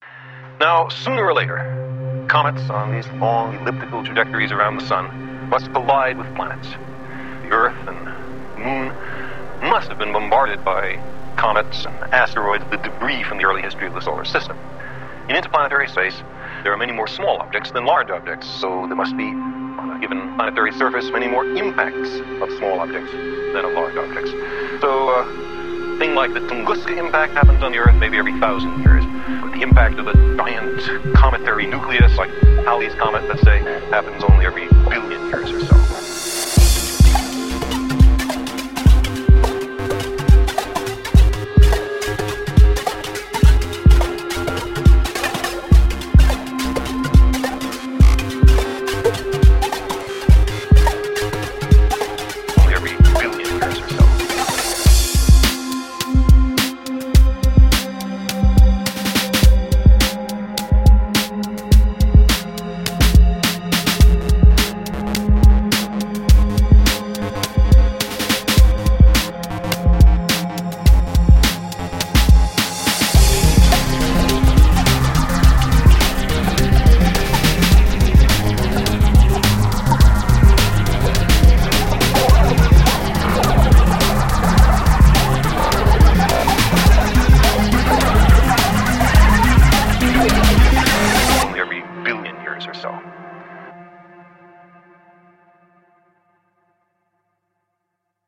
Dark, ominous effects!